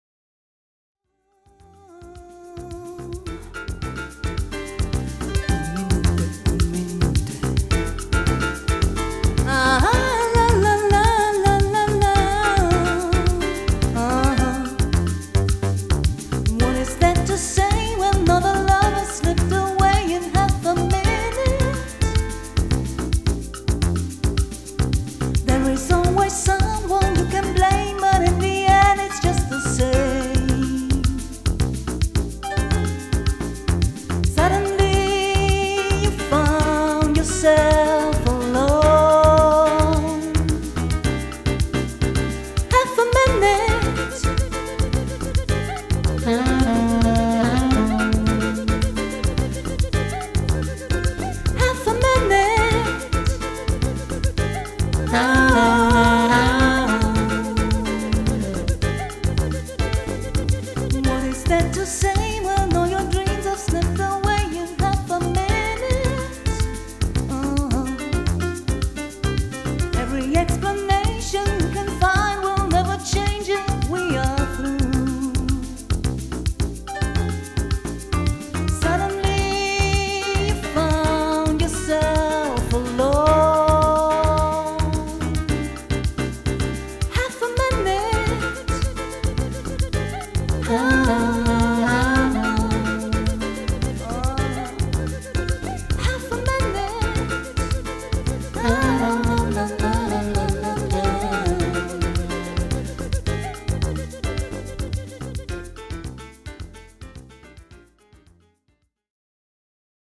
Solomusiker aus Dachau : Buchungs- und Kontaktinformationen | Solokünstlerin Saxophon Gesang QuerflöteFür Trauungen, Empfänge, Dinnermusik, Easy Listening für pri